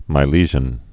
(mī-lēzhən, -shən)